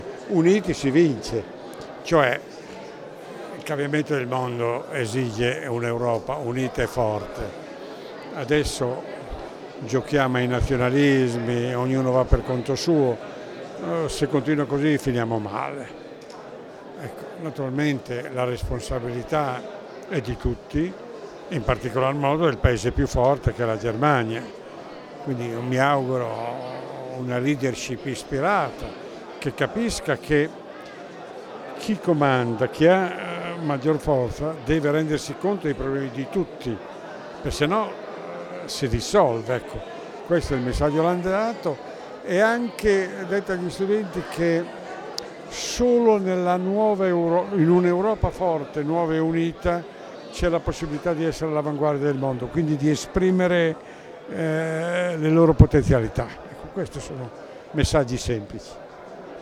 Si è svolta oggi alla FEM la lectio magistralis sull'Europa dell' ex presidente del Consiglio e della Commissione europea, Romano Prodi